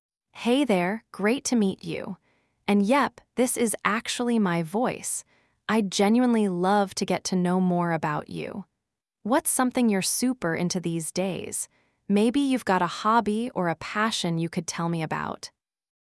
long-form-tts multi-voice text-to-speech
Kokoro v1.0 2025 Jan 27 - text-to-speech (82M params, based on StyleTTS2)
"speed": 0.8,
"voice": "af_heart"